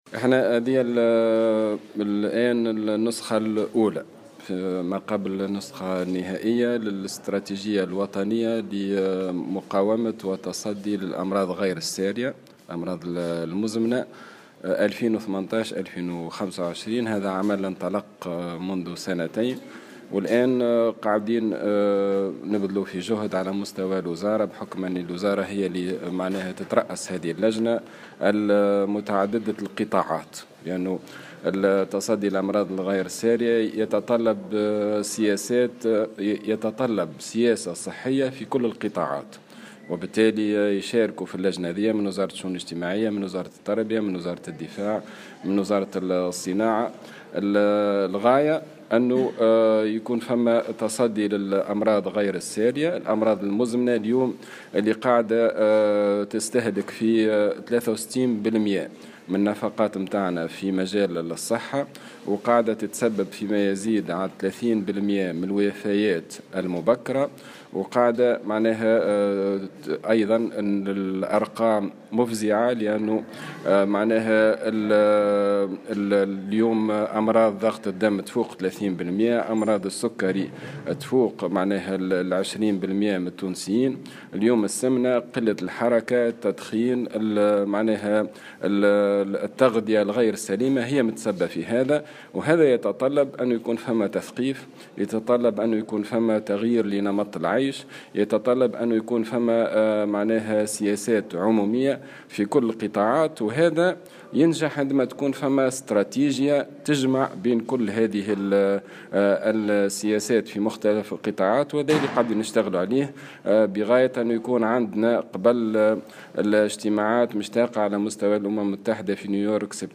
أشرف وزير الصحة عماد الحمامي اليوم الإثنين على الندوة الوطنية للحد من الأمراض غير السارية المزمنة بحضور ممثلين عن منظمة الصحة العالمية وممثلين عن وزارة التربية ووزارة التجارة.